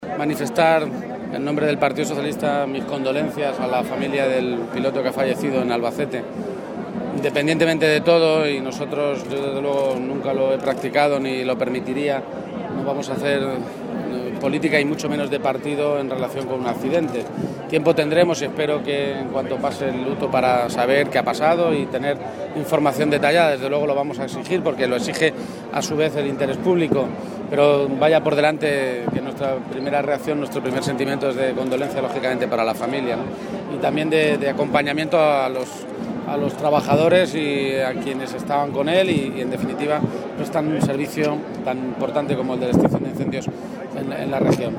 García-Page en las fiestas de Guadalajara